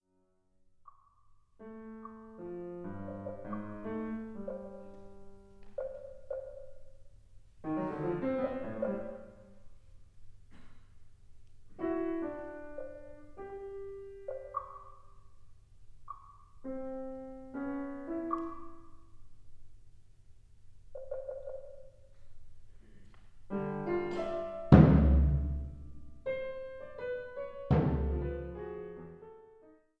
アコースティック
アバンギャルド
打楽器系
録音・編集も、ライブの緊迫した臨場感を伝えつつ、クオリティの高い仕上がり。
パーカッション&ピアノ